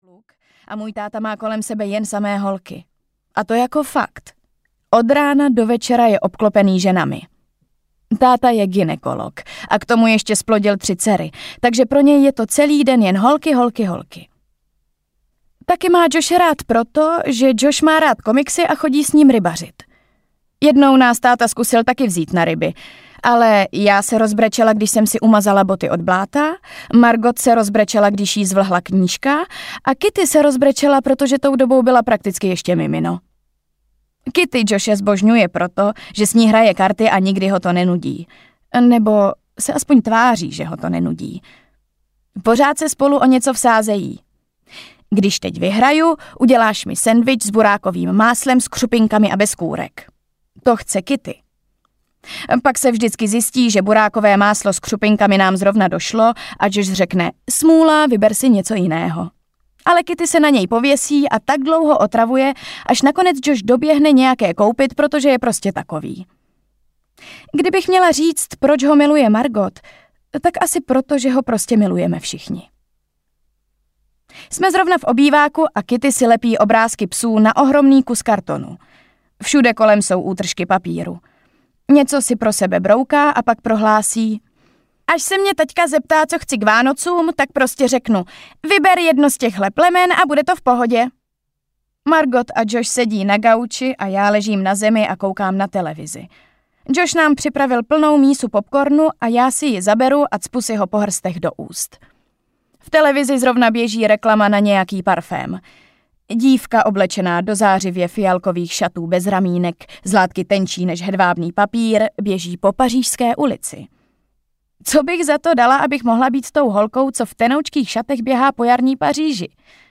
Všem klukům, které jsem milovala audiokniha
Ukázka z knihy